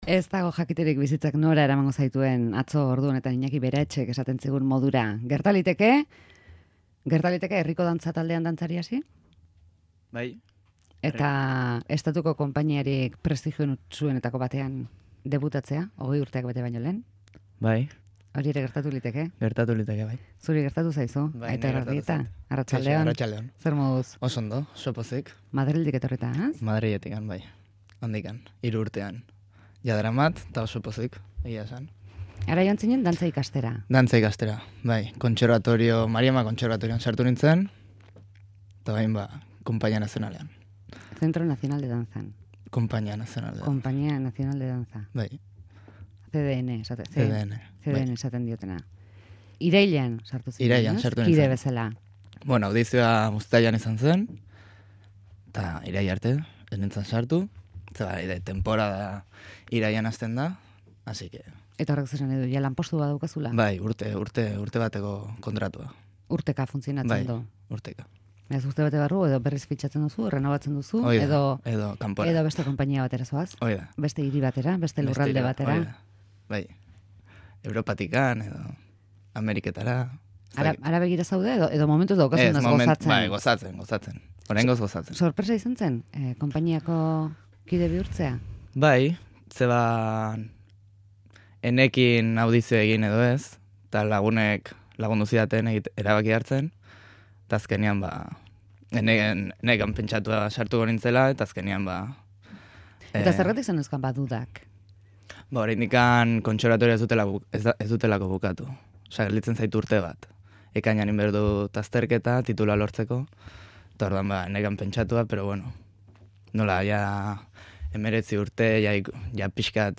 Espainiako Dantza Konpainiako dantzaria